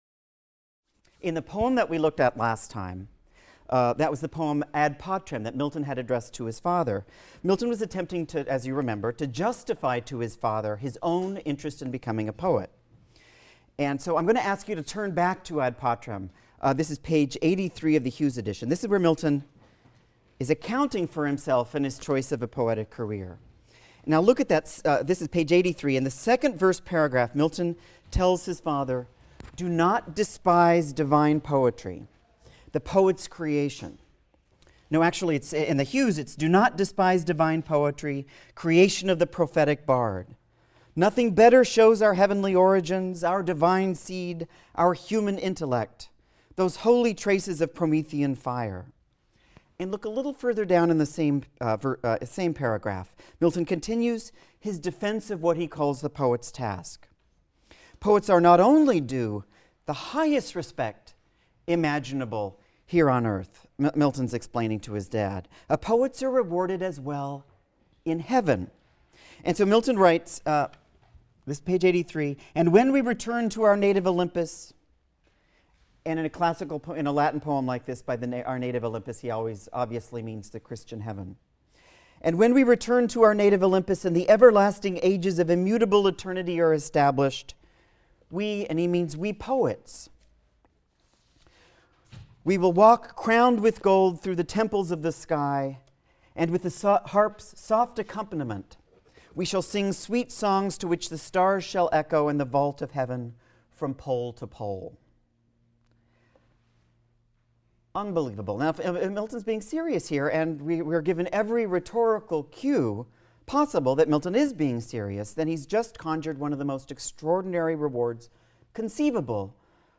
ENGL 220 - Lecture 4 - Poetry and Virginity | Open Yale Courses